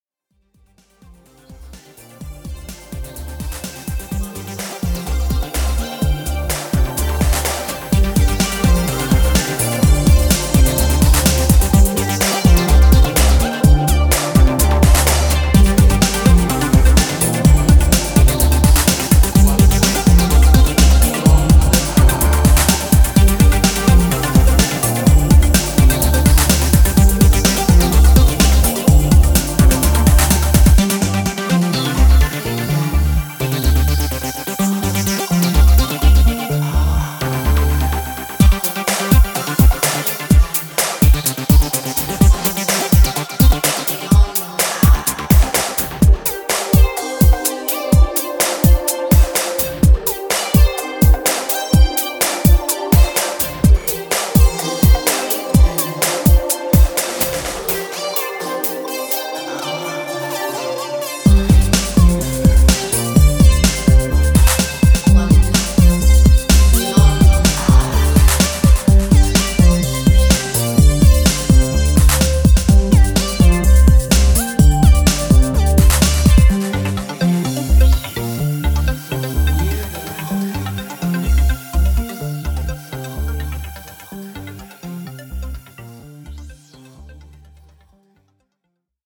フューチャリスティックなシンセワークが披露されるエレクトロ / ブレイクビーツ
ニュースクールな地下テック・ハウスを披露しています。